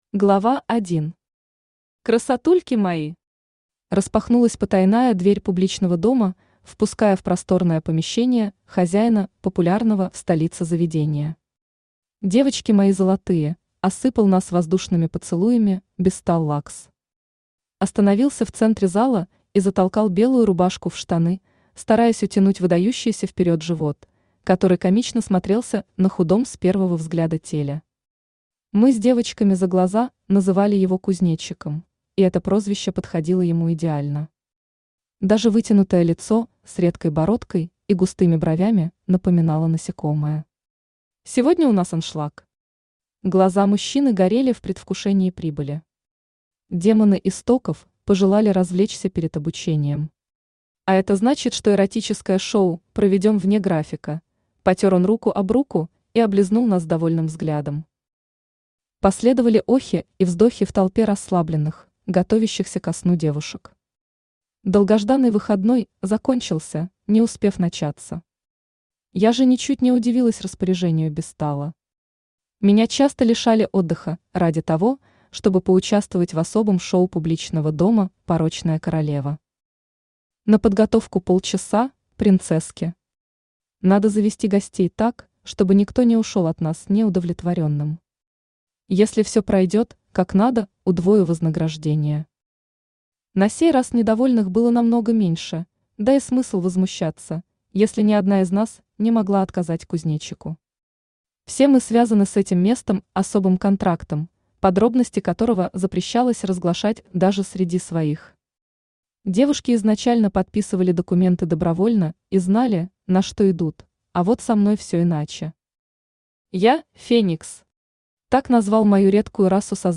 Аудиокнига Королева порока в Академии истоков | Библиотека аудиокниг
Aудиокнига Королева порока в Академии истоков Автор Юлия Пульс Читает аудиокнигу Авточтец ЛитРес.